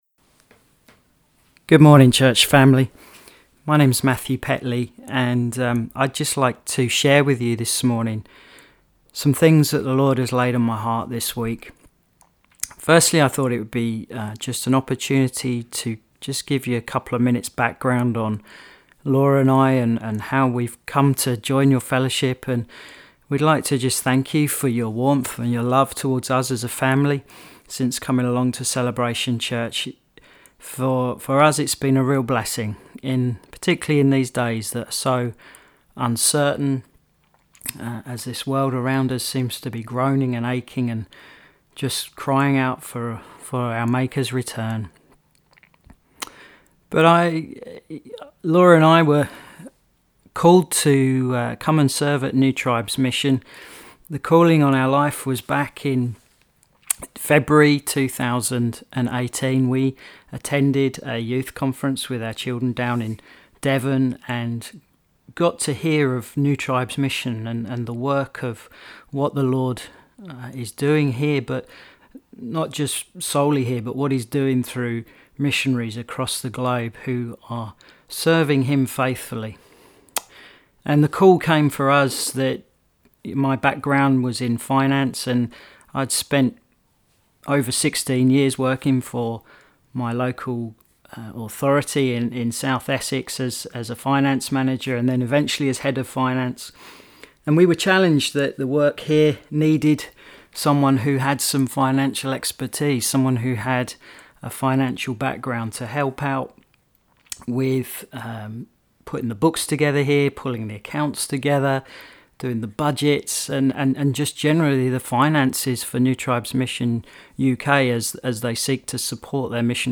Good morning everyone, welcome to the strange new world of socially distant church!